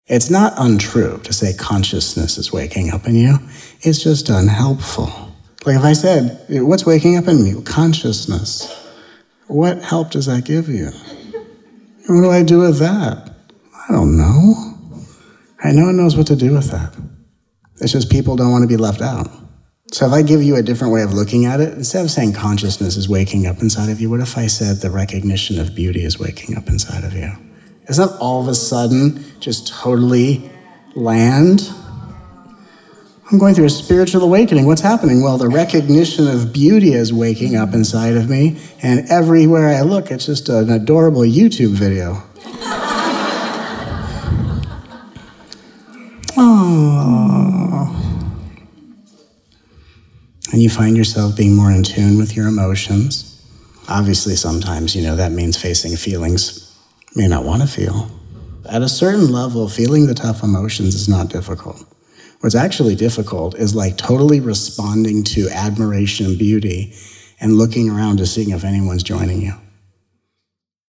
Please join us for these 14 hours of nourishing, uplifting, often hilarious, peaceful, and powerful teachings from this miraculous 5-day retreat.